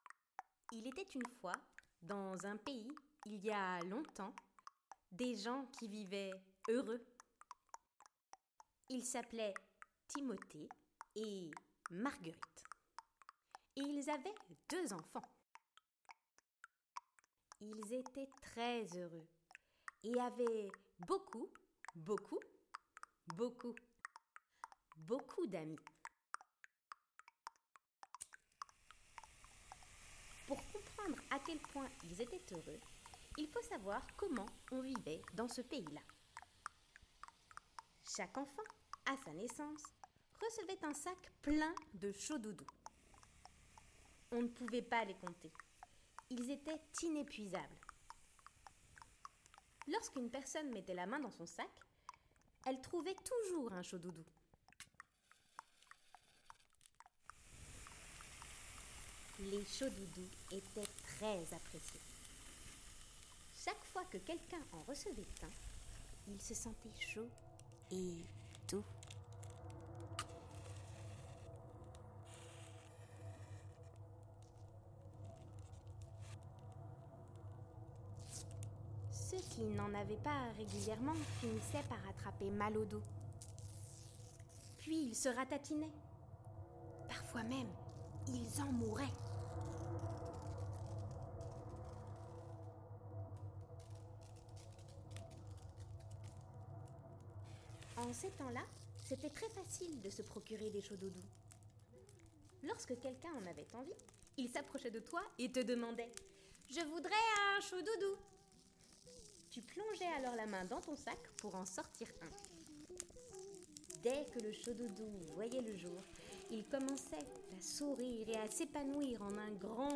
Un conte philosophie de matières pour les tous petits